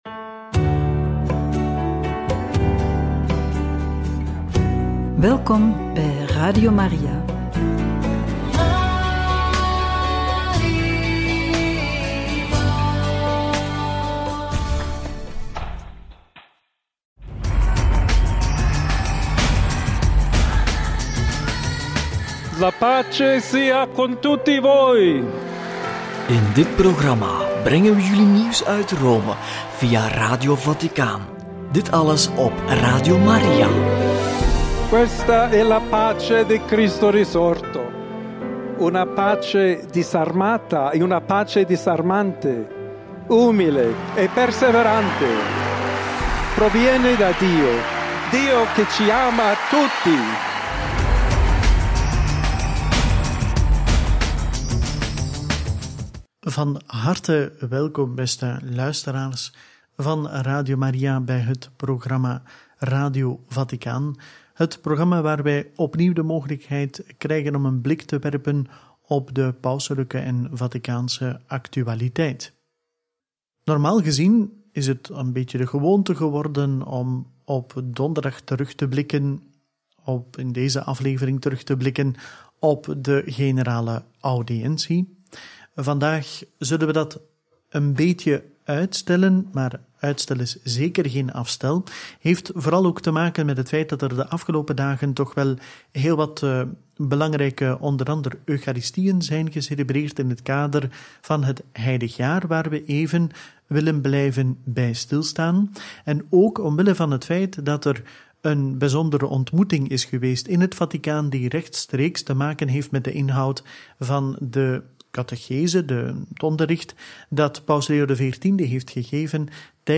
Homilie voor jubileum synodale teams en participatieorganen – Homilie voor jubileum pauselijke universiteiten – Radio Maria